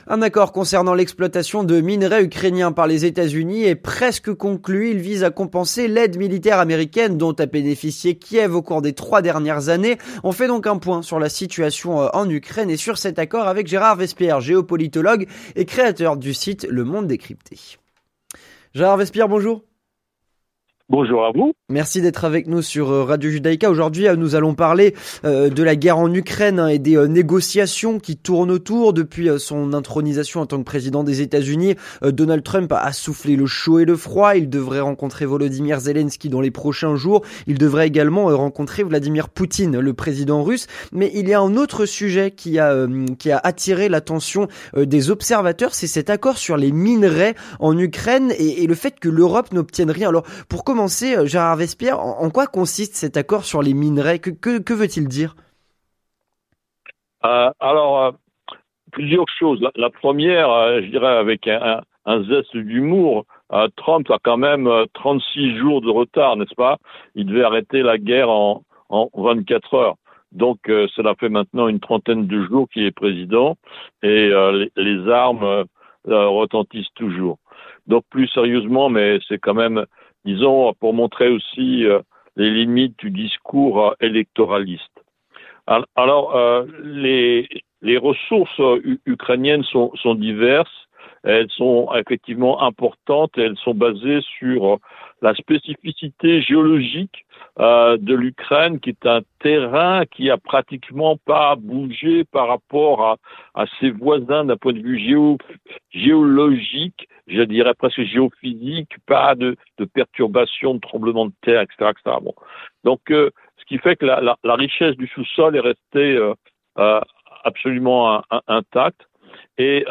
L'entretien du 18H - L’accord concernant l’exploitation de minerais ukrainiens par les États-Unis est (presque) conclu.